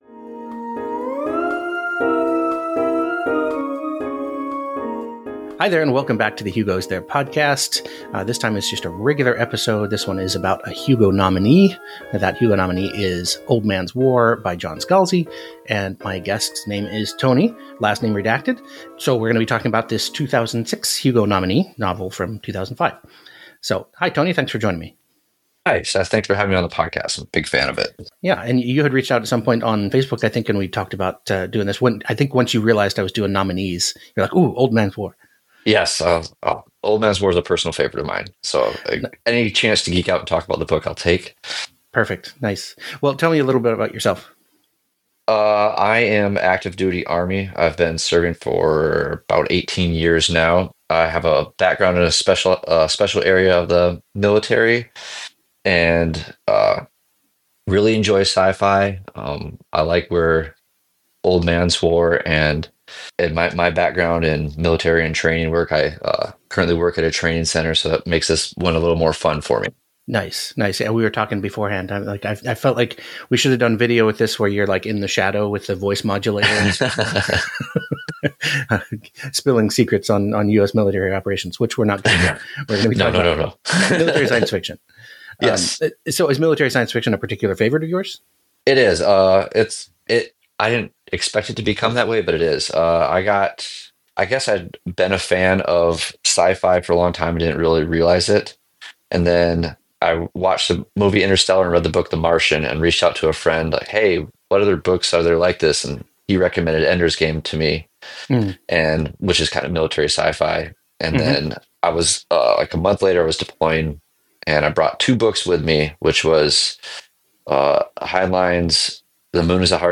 My guest and I discuss a Hugo-nominee, Old Man’s War, by John Scalzi.